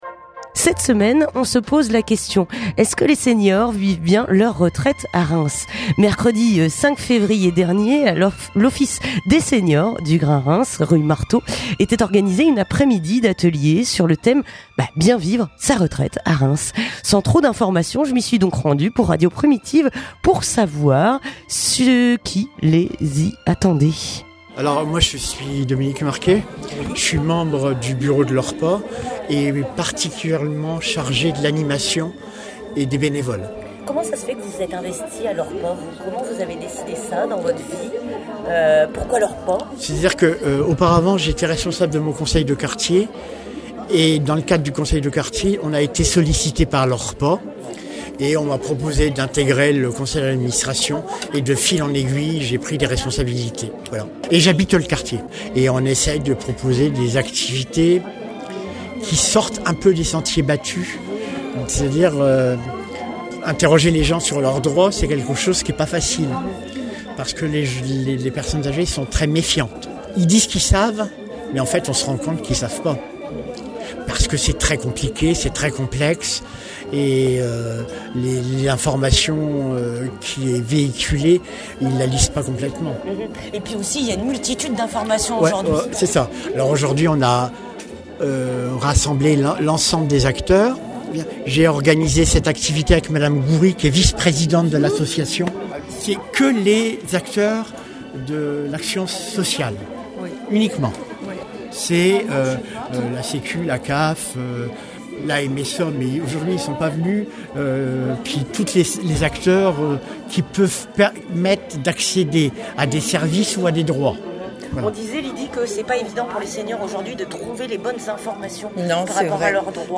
Reportage à l'Orrpa (14:18)